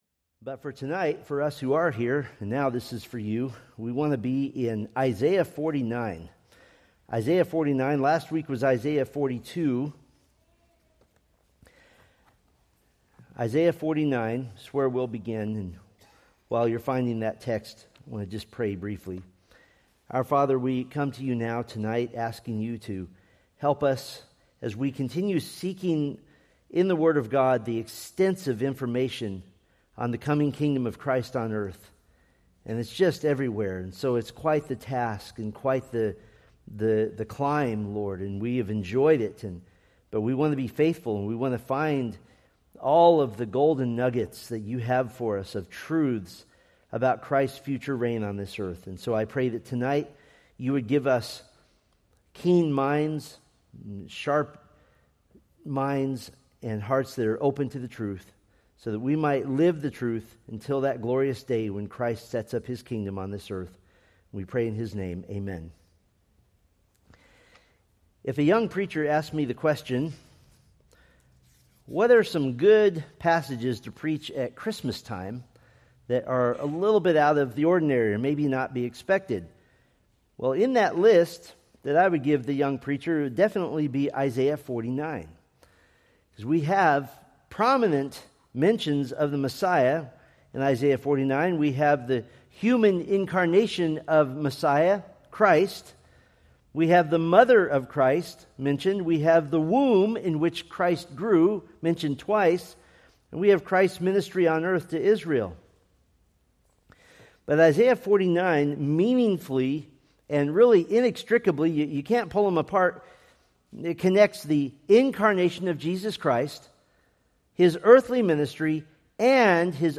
Preached November 16, 2025 from Selected Scriptures